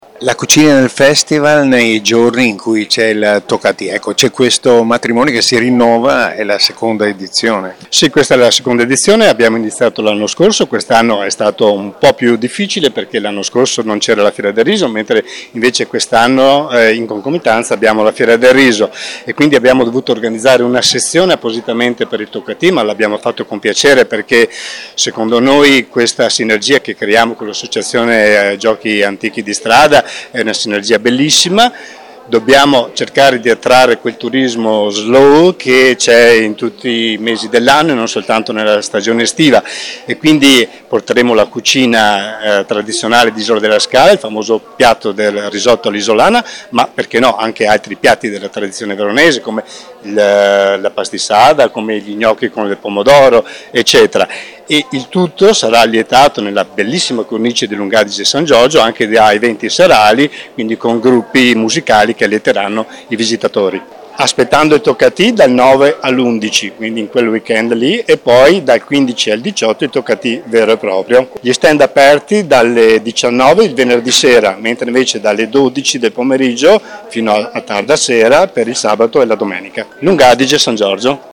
Ecco le interviste realizzate durante la conferenza stampa di presentazione: